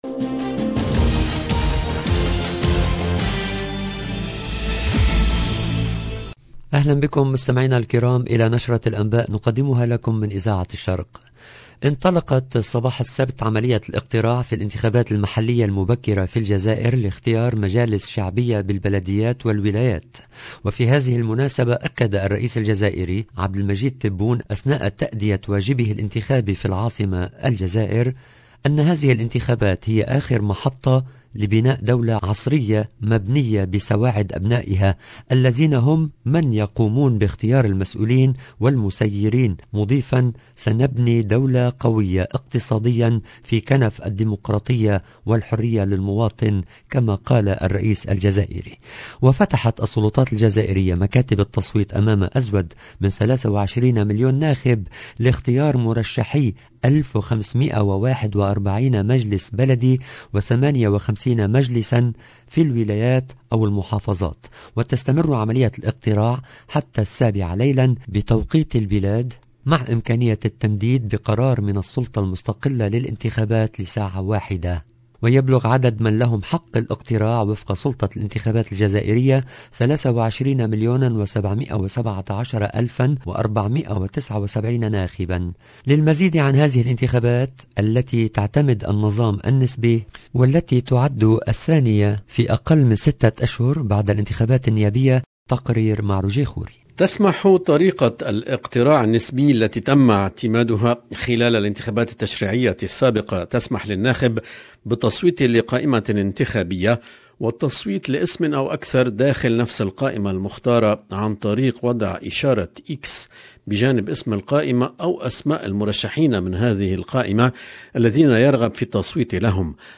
LE JOURNAL EN LANGUE ARABE DU SOIR DU 27/11/21